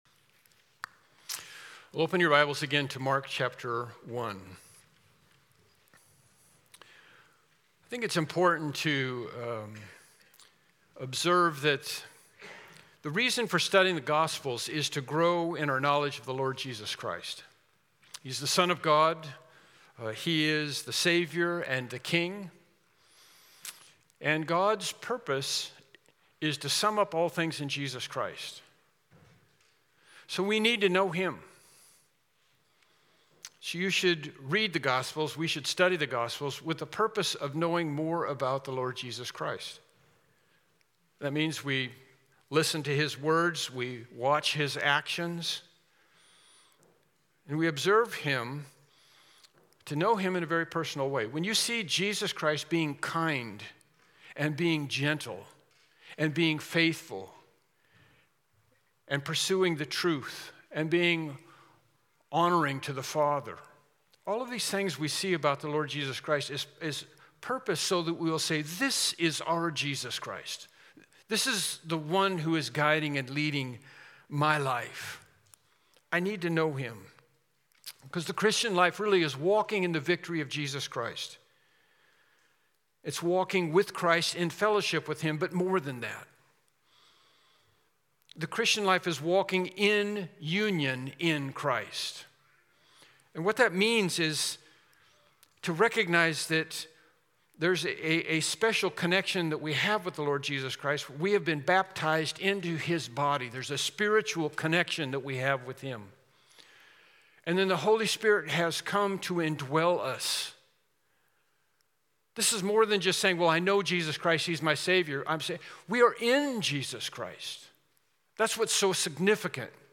Mark 1:14-15 Service Type: Morning Worship Service « Lesson 9